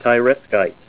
Help on Name Pronunciation: Name Pronunciation: Tyretskite + Pronunciation
Say TYRETSKITE